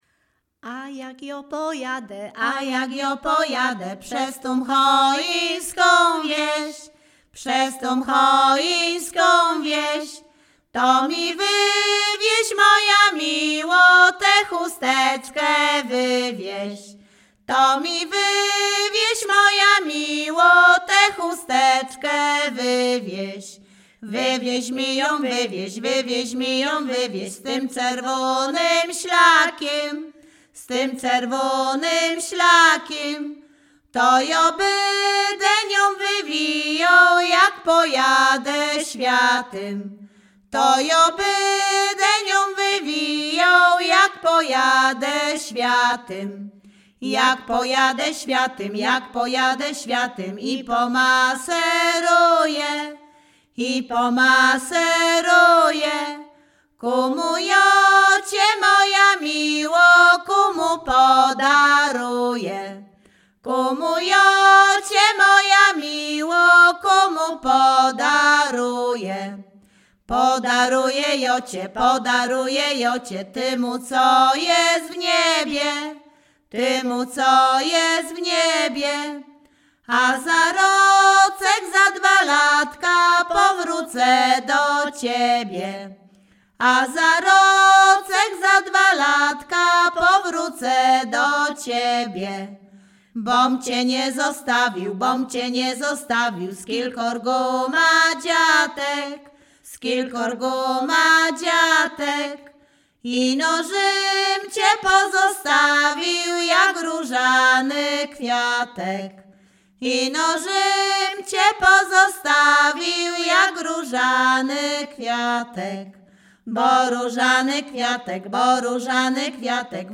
Śpiewaczki z Chojnego
województwo łódzkie, powiat sieradzki, gmina Sieradz, wieś Chojne
liryczne miłosne żartobliwe